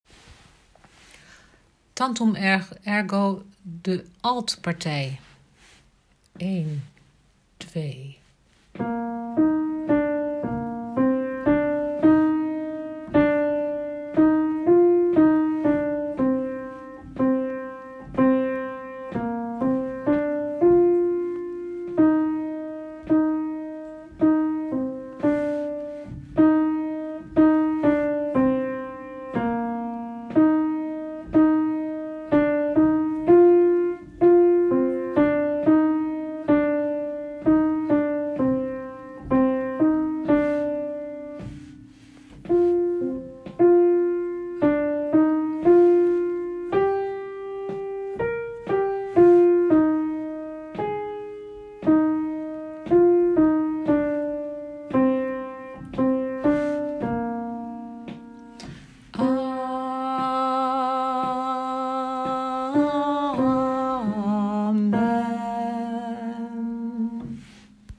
tantum geluid sopraan